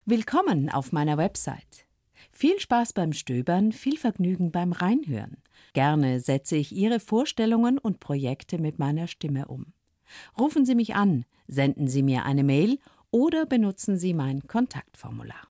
Sie suchen eine professionelle Sprecherin mit Engagement, großer stimmlicher Bandbreite und Interesse an ihren Texten?